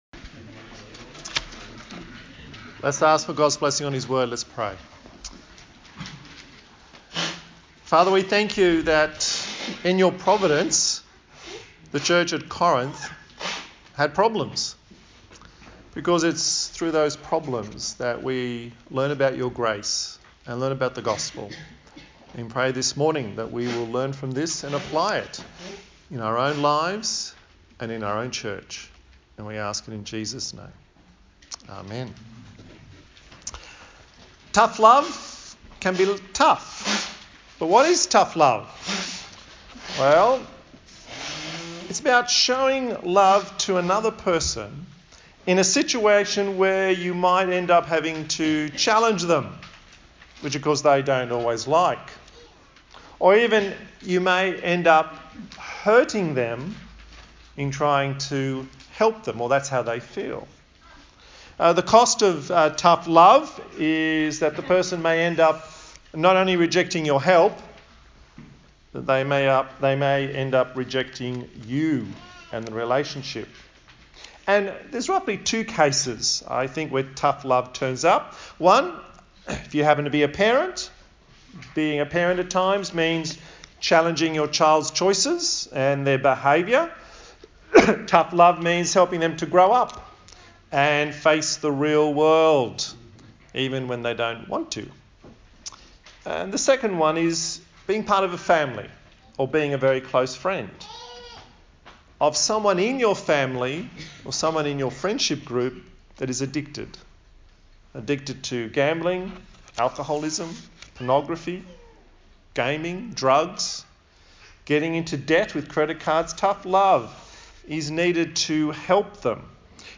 A sermon
Service Type: Sunday Morning